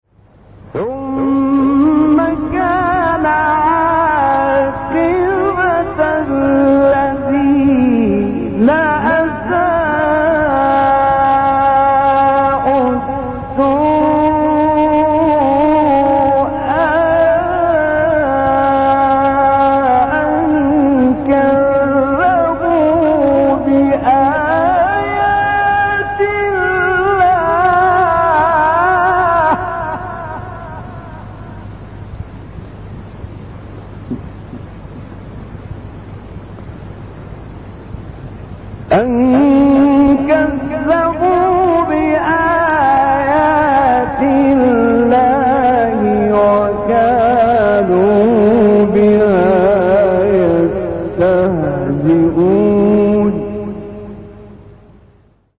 صوت | تلاوت آیه 10«سوره روم» با صوت قاریان شهیر
تلاوت آیه 10 سوره روم با صوت راغب مصطفی غلوش